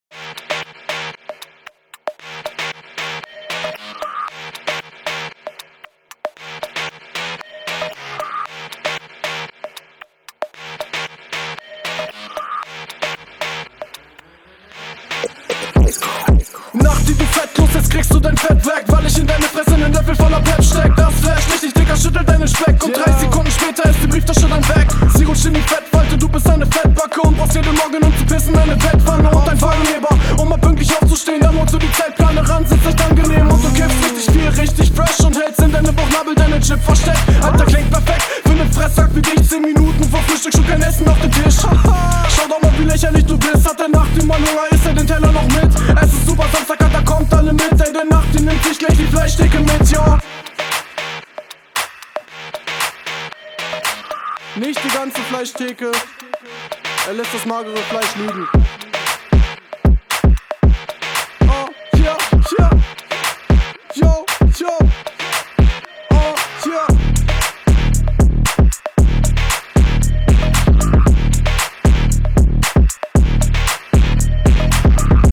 Der Beat gettet einen hyped wie man so schön sagt. Guter Flow, Stimmeinsatz ist stark.
Klang ist super, alles gut …
Beat knallt. Flow geht schön nach vorne, bist manchmal aber leicht unverständlich.